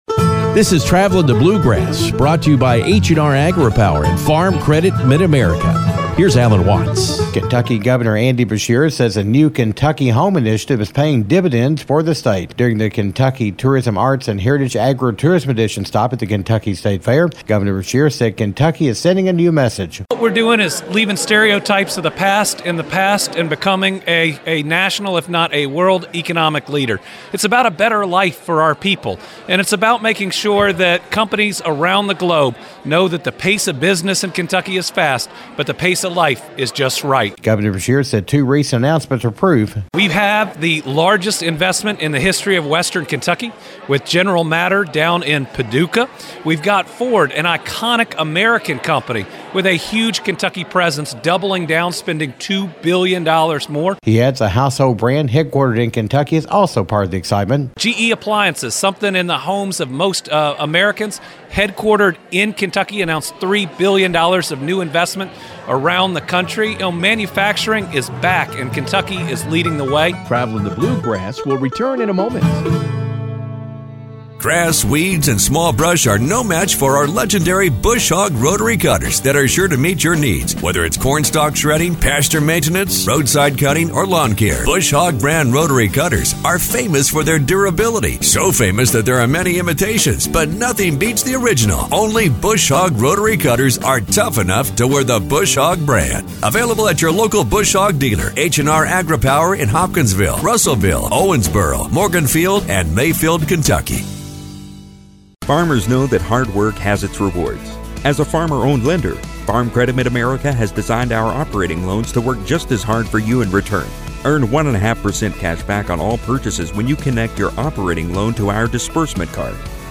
Kentucky Governor Andy Beshear says the New Kentucky Home initiative is paying off with several recent economic development announcements. During a Kentucky State Fair visit as a part of the Agritourism Edition Governor Beshear talked about how the initiative is combining tourism and economic development to attract new business and industry.